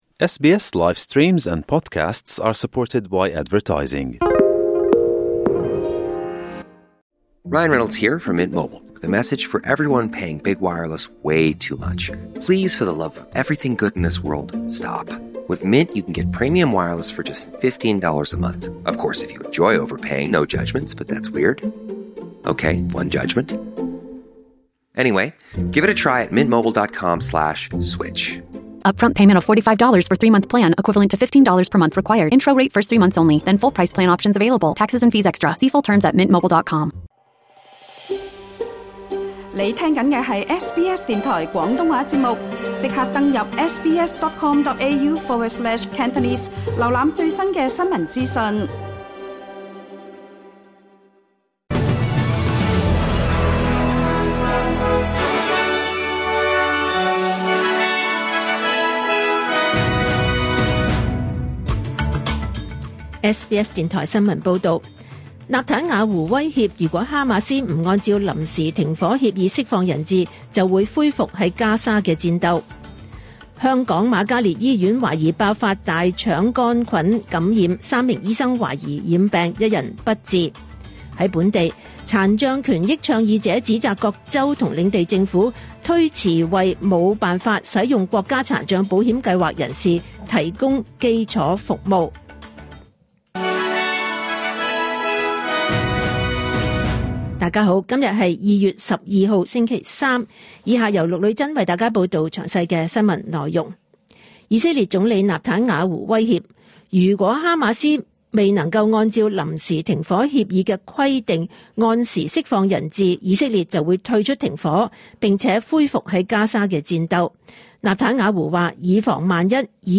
2025 年 2 月 12 日 SBS 廣東話節目詳盡早晨新聞報道。